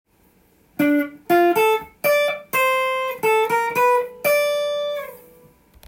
譜面通り弾いてみました
①のフレーズは独特の裏拍を意識したポップなフレーズです。
コードトーンとクロマチックスケールを混ぜたオシャレで